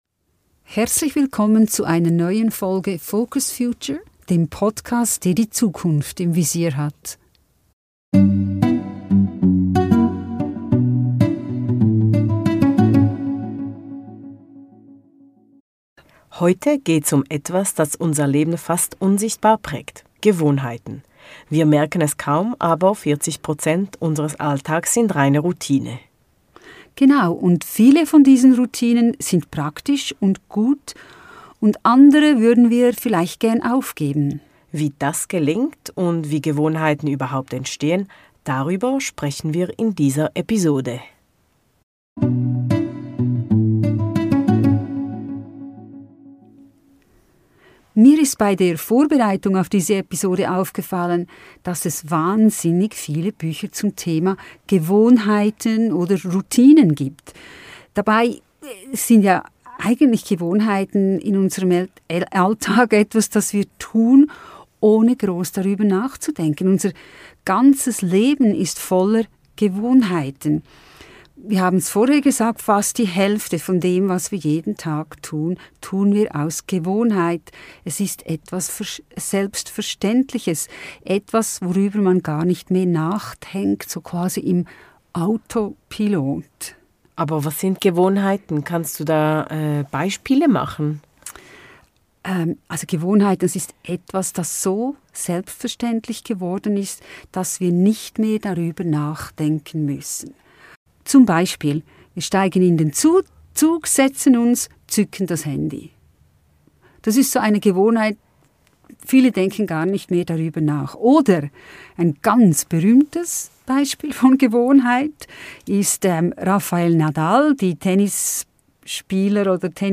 Gewohnheiten - kaum sichtbar, doch so mächtig ~ FocusFuture - Zwei Generationen sprechen über die Zukunft.